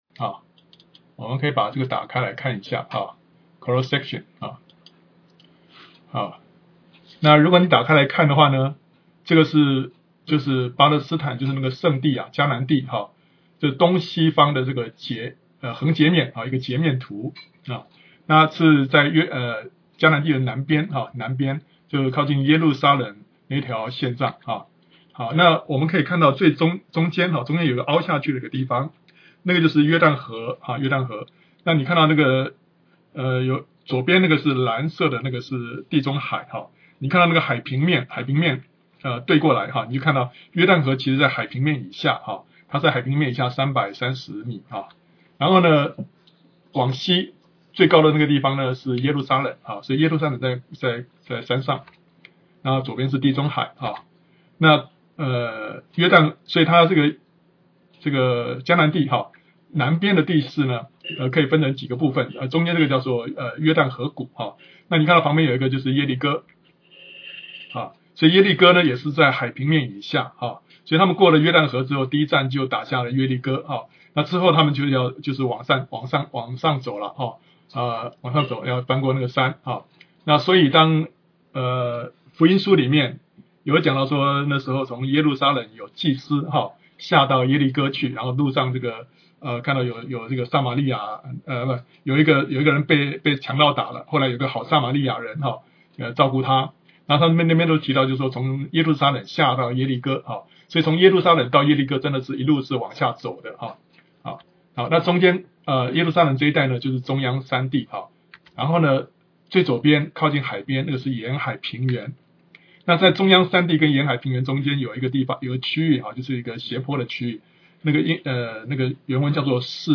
"圣经简报站"的内容，是温哥华一华人基督徒查经班的查经资料和录音，欢迎主内肢体参考使用。